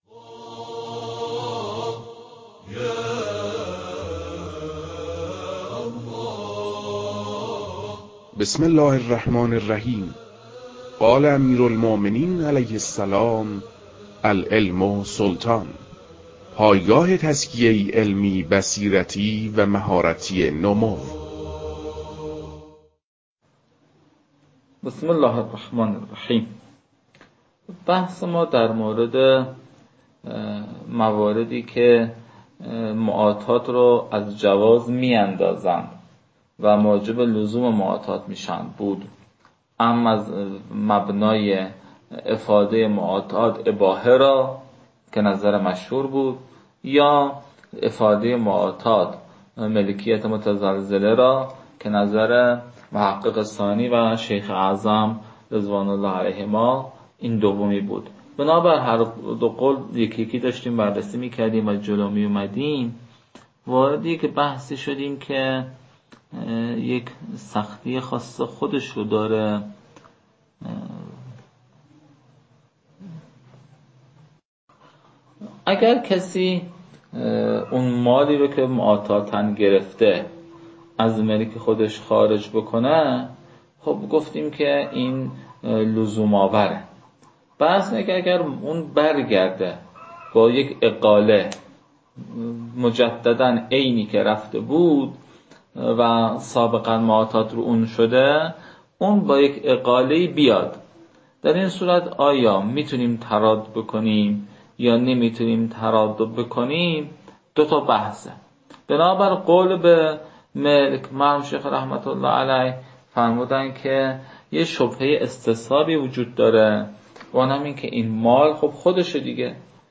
تدریس مباحث تنبیهات معاطات از كتاب المكاسب